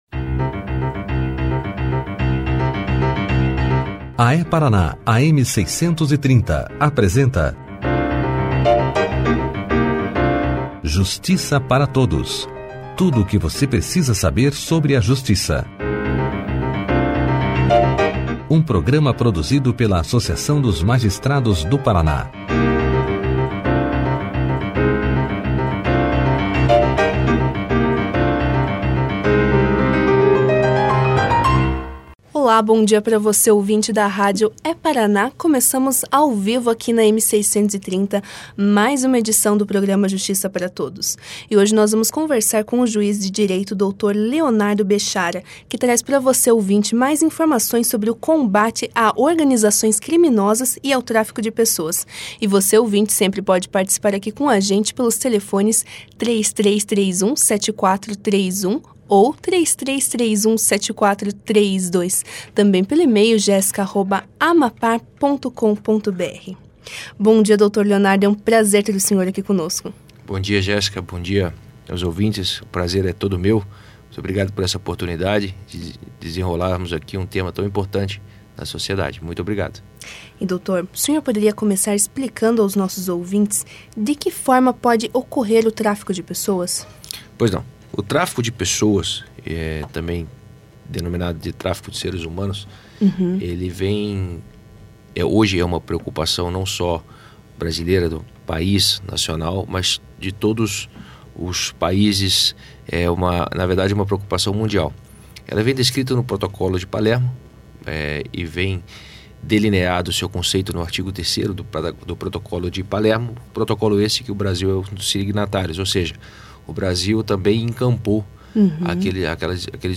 No programa Justiça Para Todos dessa quarta-feira (26), o juiz Leonardo Bechara falou aos ouvintes da rádio É-Paraná sobre o combate a organizações criminosas e ao tráfico de pessoas.
Clique aqui e ouça a entrevista do juiz Leonardo Bechara sobre organizações criminosas e tráfico de pessoas na íntegra.